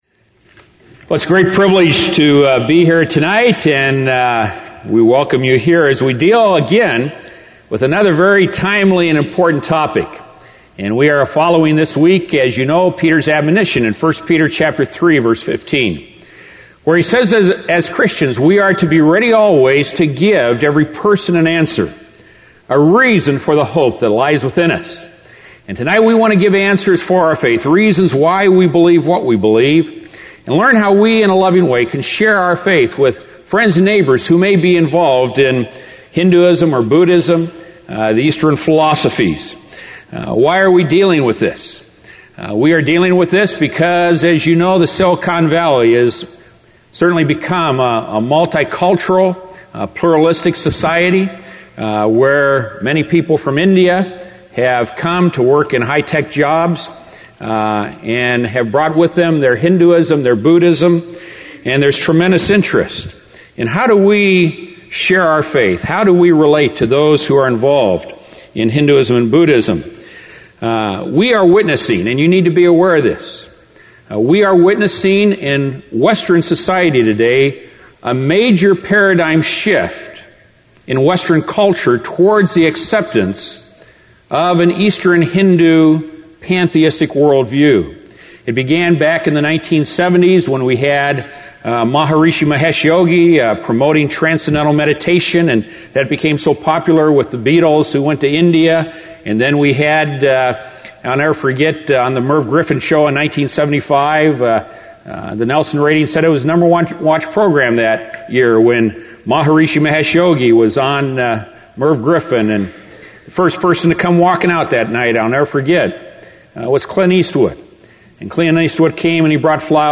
A revealing lecture that will give you answers for your Faith.